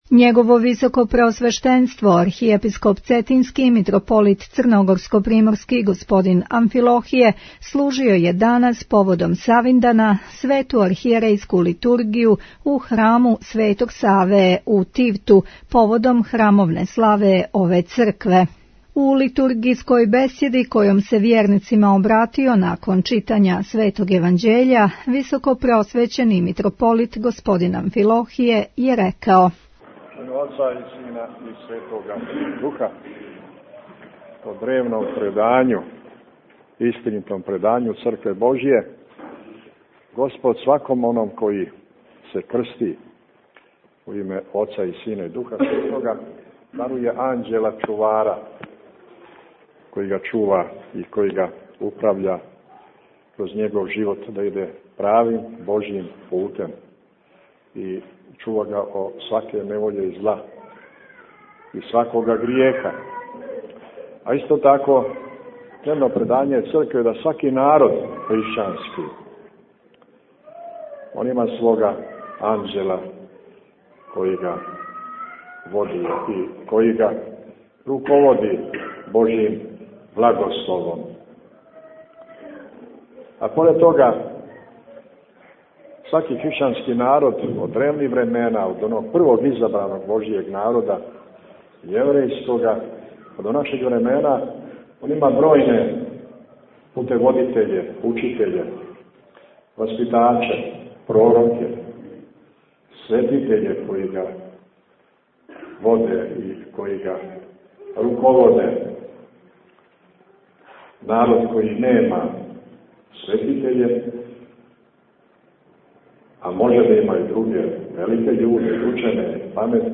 Бесједе
Преузмите аудио датотеку 105 преузимања 8 слушања Митрополит Амфилохије служиo на Савиндан Литургију у цркви Светог Саве у Тивту Tagged: Бесједе 30:25 минута (5.22 МБ) Светом архијерејском литургијом, благосиљањем славског колача, дјечјим светосавским програмом и подјелом дјеци светосавских поклон пакетића у цркви Светог Саве у Тивту данас је прослављен празник Светог Саве, првог Архиепископа српскога.